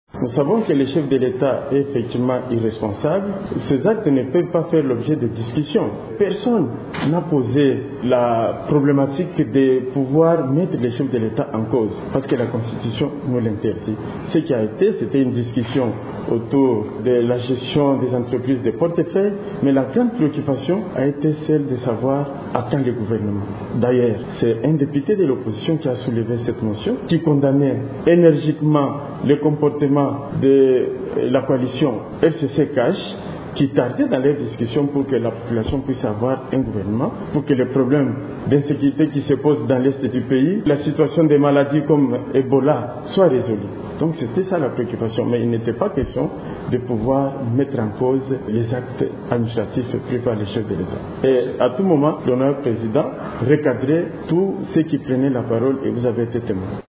Dans une interview accordée à la presse, le rapporteur de l’Assemblée nationale a par ailleurs invité les responsables de l’UDPS à calmer leurs militants.